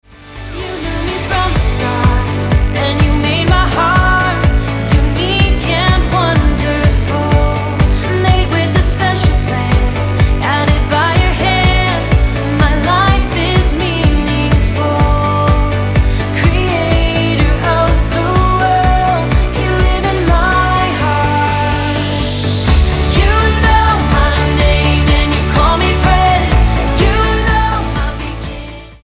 Style: Childrens